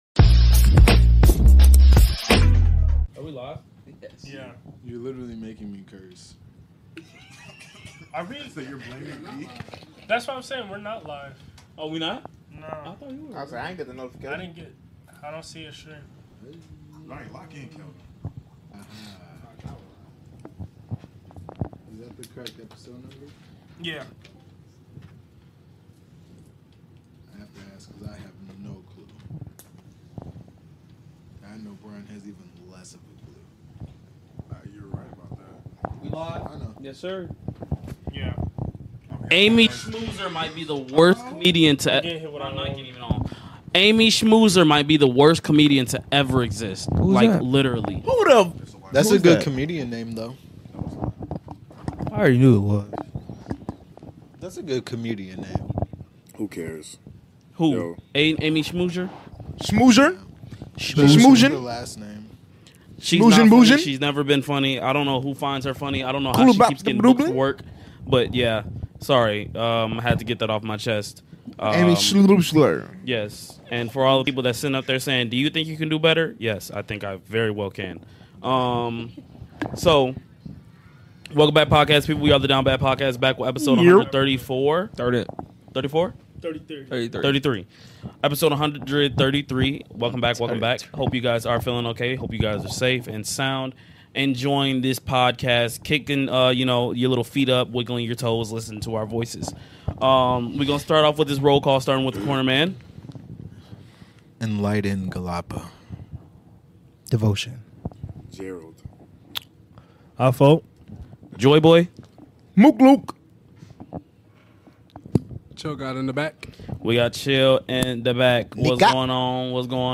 Group of 6 guys just trying to make it in life whether it be YouTube, producing, music, real estate, etc. We talk about many things that are sometimes easy or hard to communicate.